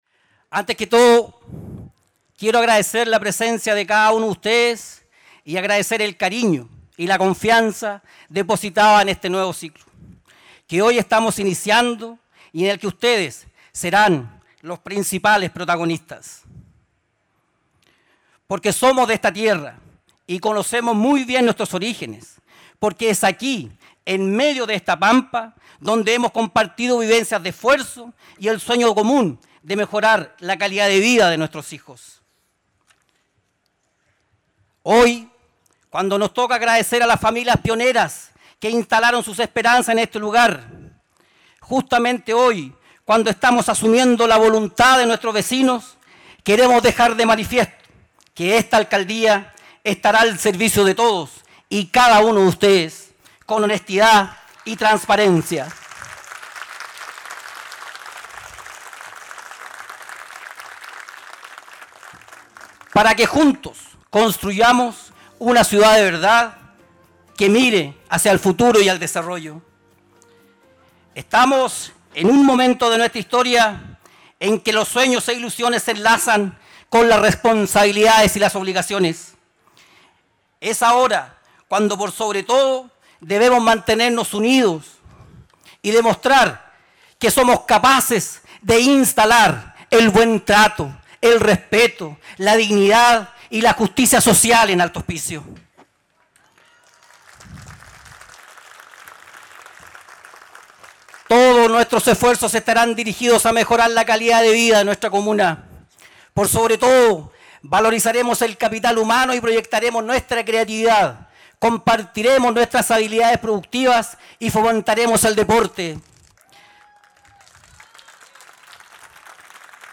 A las 11:00 horas del pasado 6 de diciembre se dio inicio al Concejo Municipal Solemne de Alto Hospicio, en el que asumió el nuevo alcalde de la comuna, Patricio Ferreira Rivera.
Mensaje Alcalde:
Discurso-Alcalde-Patricio-Ferreira.mp3